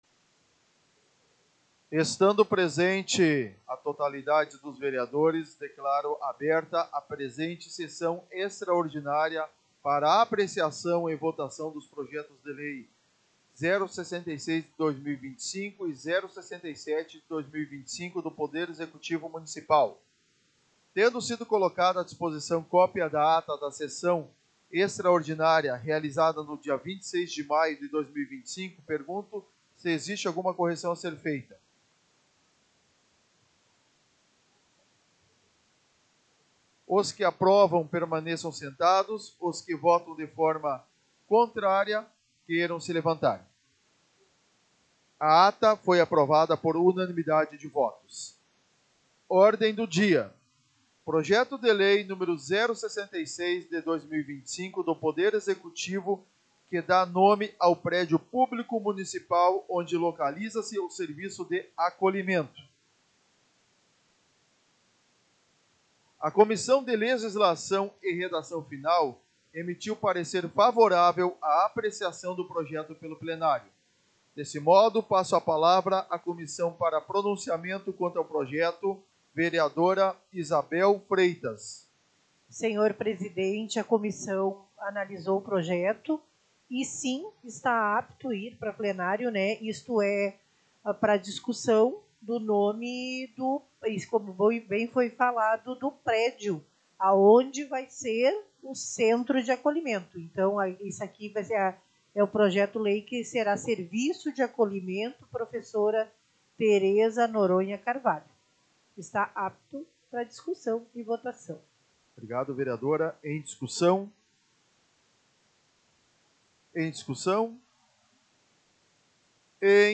Áudio Sessão Extraordinária 14.07.2025